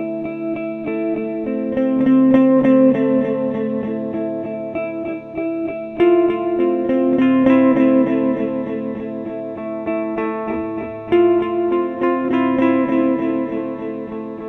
mix 1 and a loopable wav file. loopable files are clips cut just before and after loop points for someone else to do the fun part.